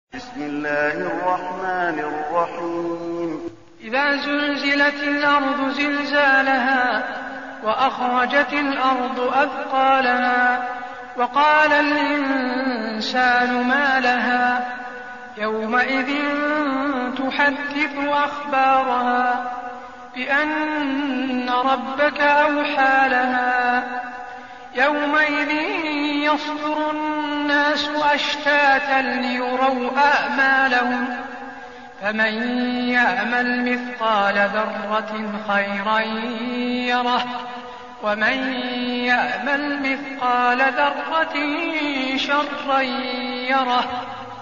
المكان: المسجد النبوي الزلزلة